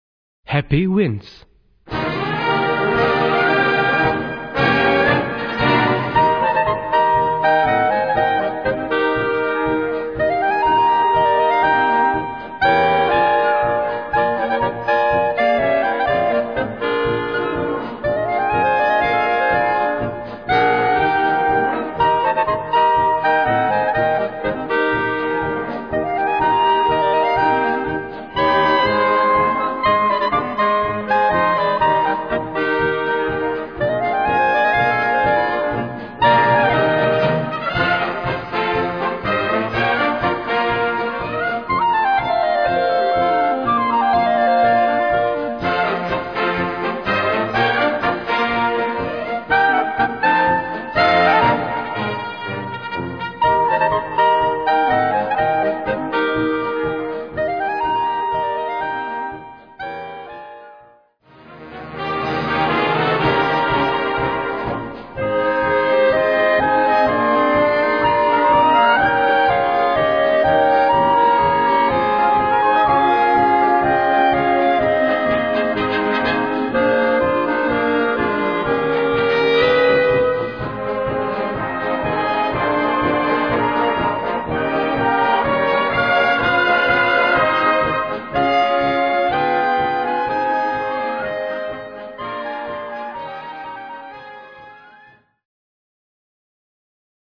Gattung: Solo für 4 Klarinetten
Besetzung: Blasorchester
und Blasorchester.